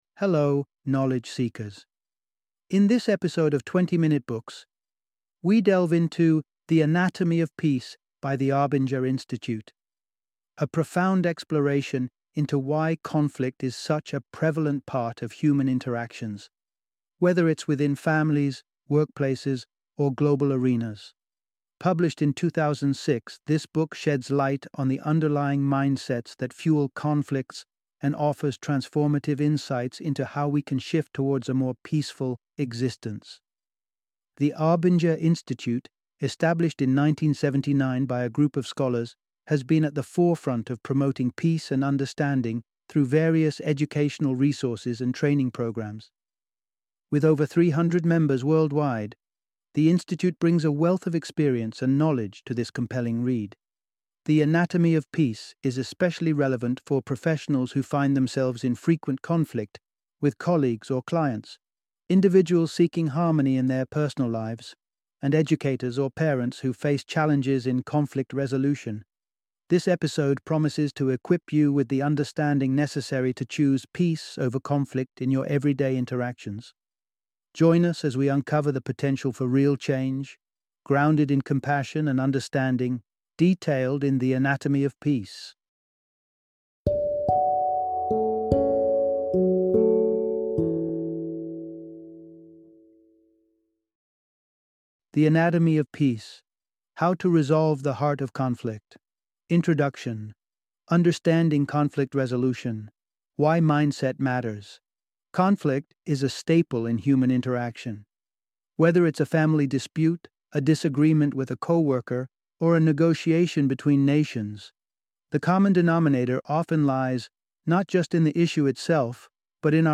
The Anatomy of Peace - Audiobook Summary